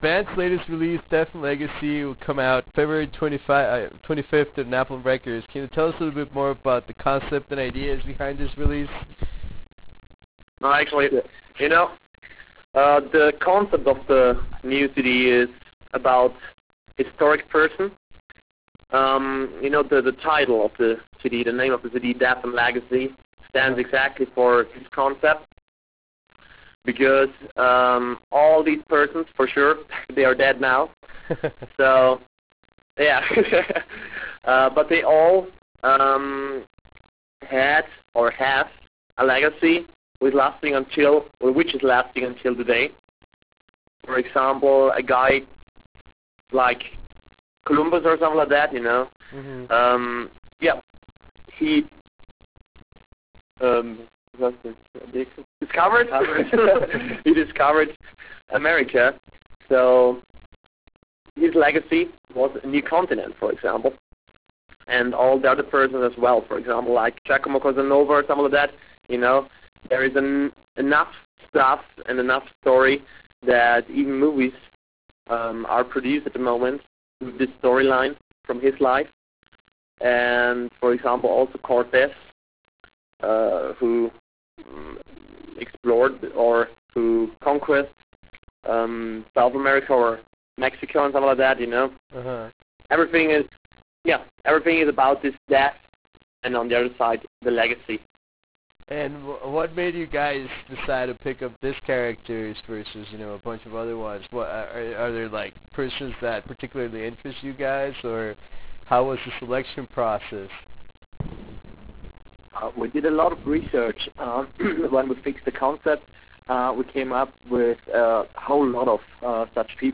On the European release date of their epic “Death & Legacy” album, we managed to score a conversation with two members of the band.
We also talk about the whole recording process behind such and ambitious release and how they managed to get Charlotte Wessels, Amanda Somerville and Ailyn to guest appear in a couple of songs. To listen to this 20+ minute interview click HERE, or Right Click and select Save As to take it with you.